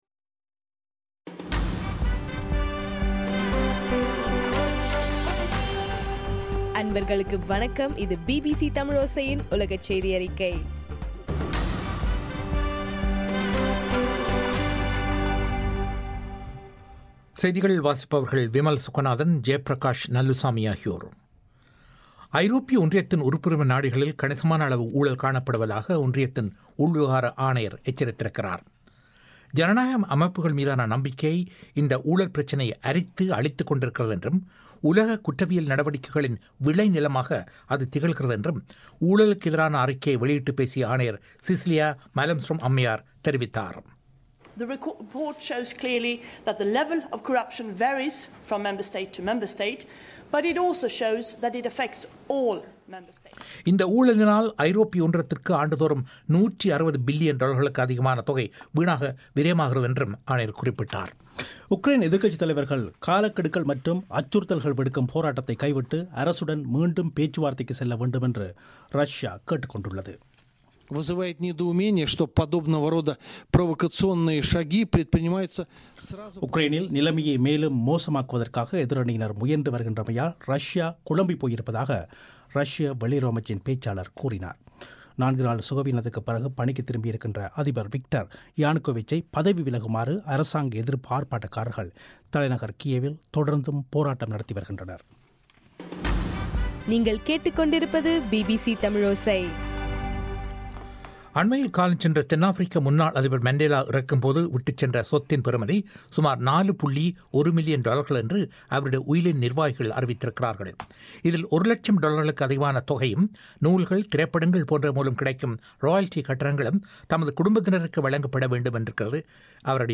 பிபிசி தமிழோசை உலகச் செய்தி அறிக்கை ( பிப்ரவரி 3)